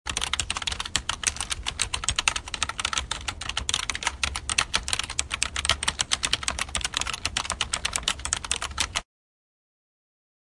在键盘上打字
描述：在键盘上打字。 Zoom H2n Handy Recorder用于捕获在基本计算机键盘上输入的声音。基本编辑在GarageBand中完成。
标签： 打字 办公 计算机
声道立体声